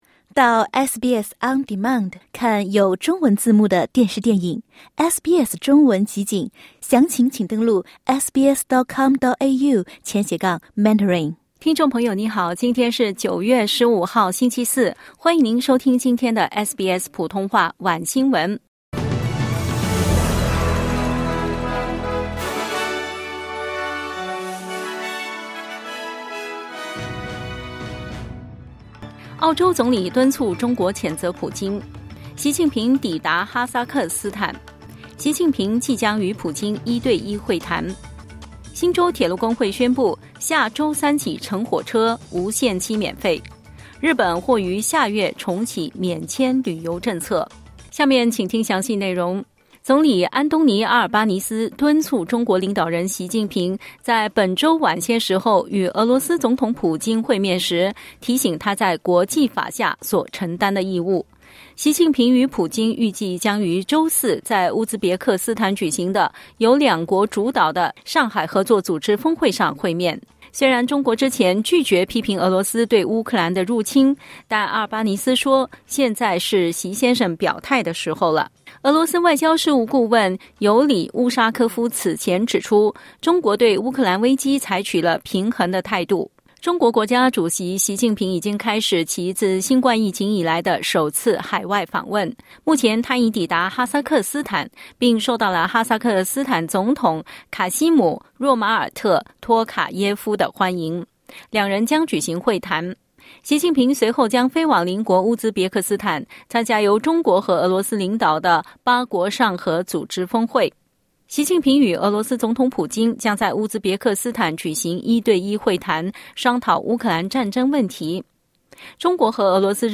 SBS普通话晚新闻（15/09/2022）